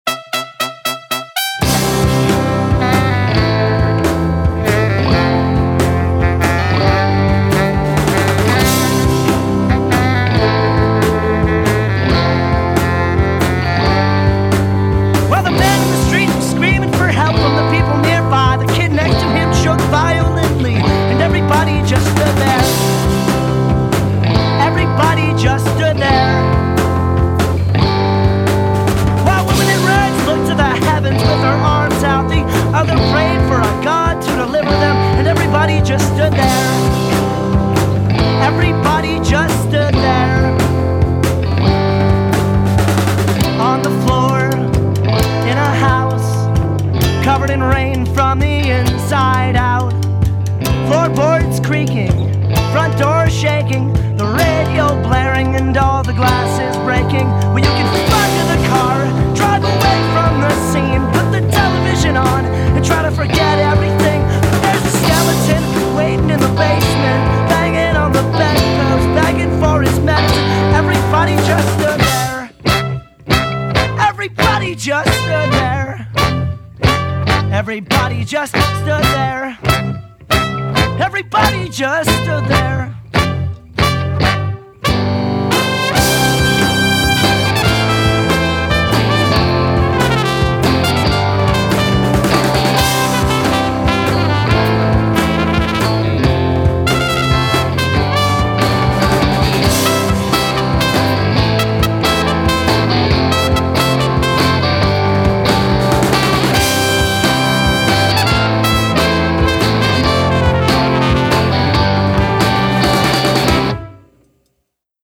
Percussion
bass, mandolin
trumpet
saxophones
banjo, piano
trombone